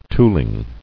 [tool·ing]